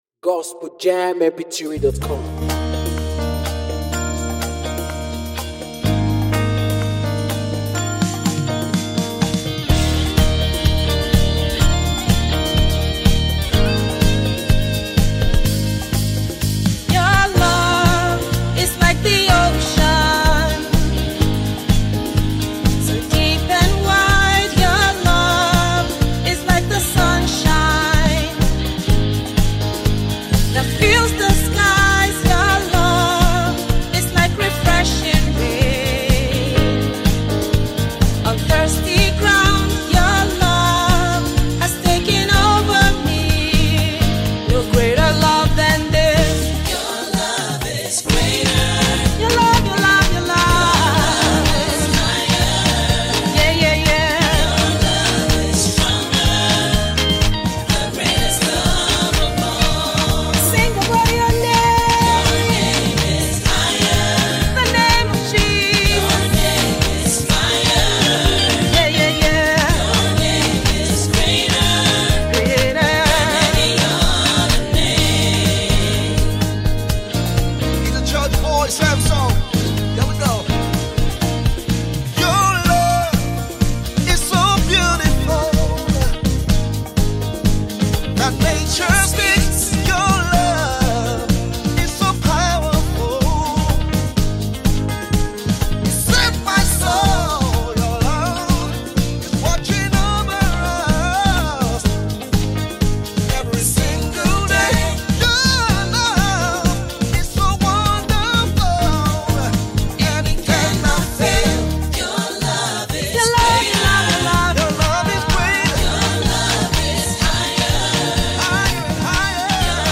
soulful and inspirational